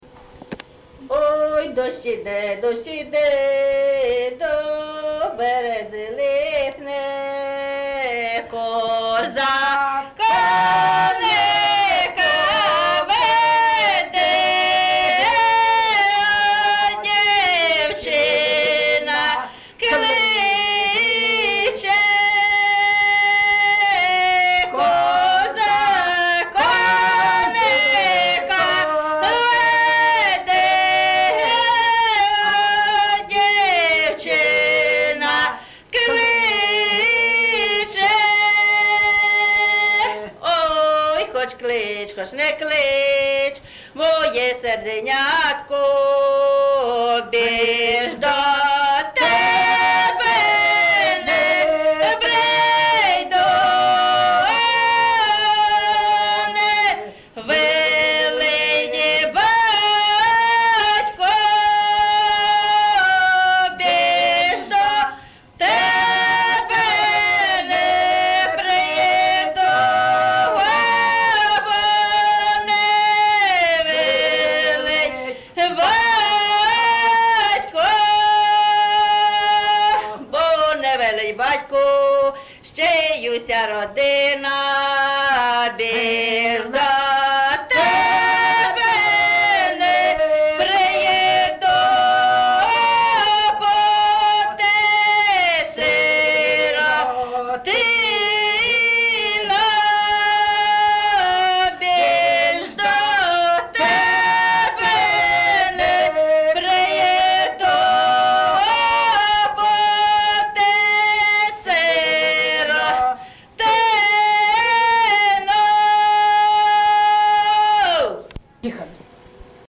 ЖанрПісні з особистого та родинного життя, Козацькі
Місце записус. Яблунівка, Костянтинівський (Краматорський) район, Донецька обл., Україна, Слобожанщина